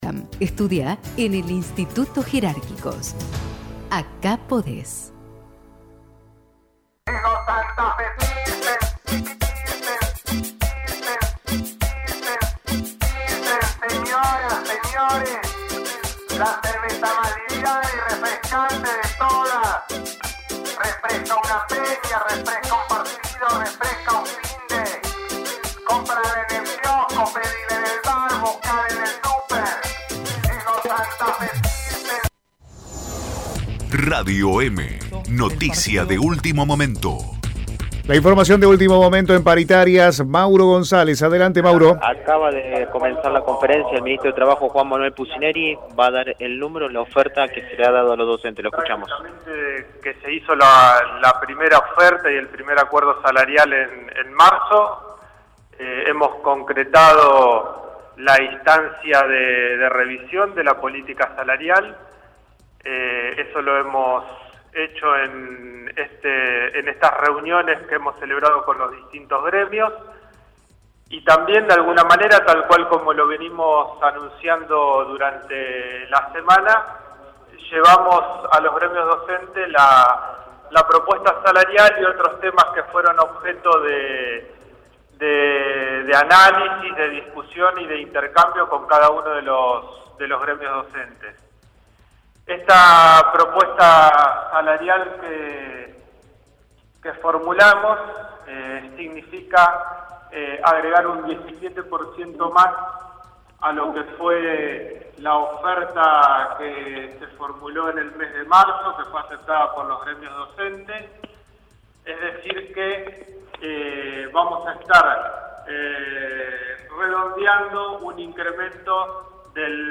Escuchá la conferencia de prensa de Juan Manuel Pusineri
CONF-PUSINERI-PARITARIAS-DOCENTES.mp3